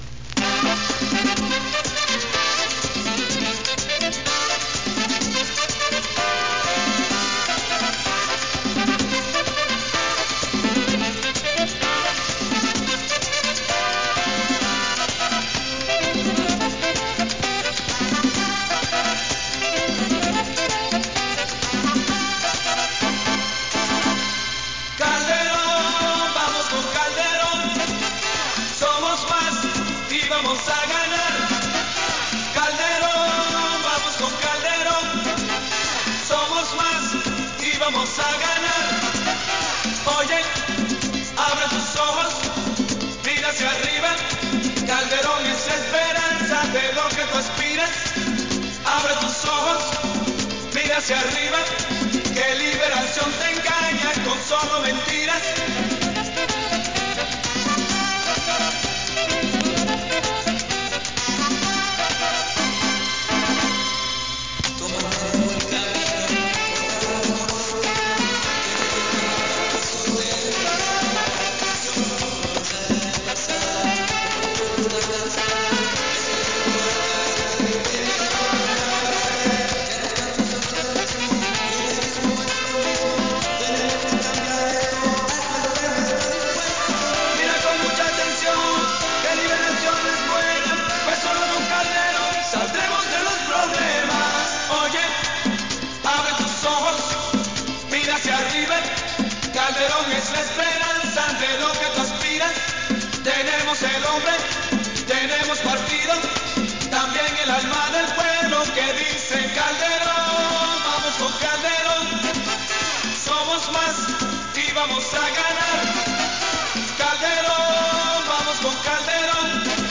Cuñas radiales de la campaña política del Partido Unidad Social Cristiana. Candidato a la presidencia de la República de Costa Rica Rafael Ángel Calderón Fournier
Notas: Cassette 99